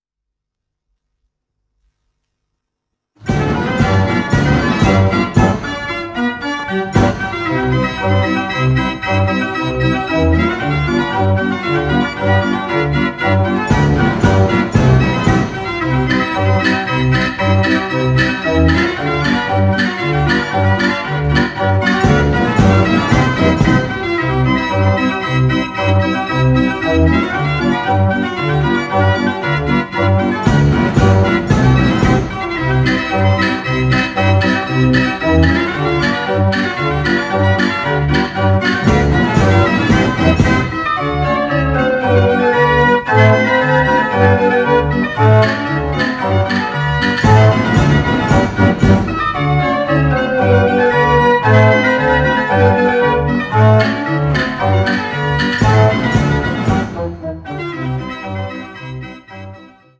Nostalgie in Stereo